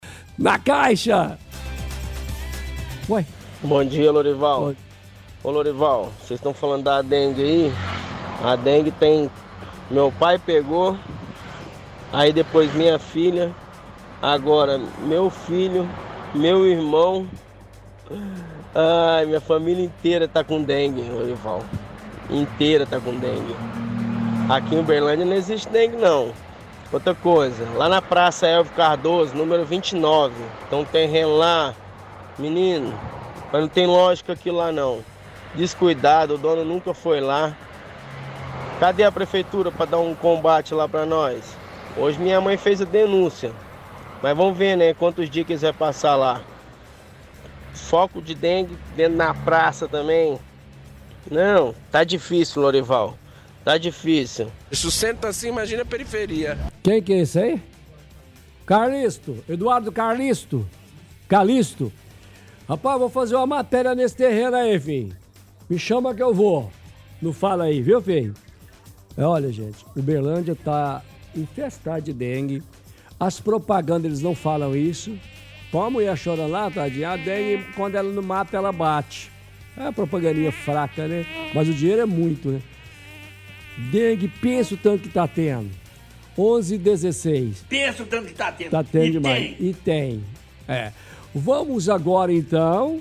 – Ouvinte relata vários membros da família dele que estão com dengue.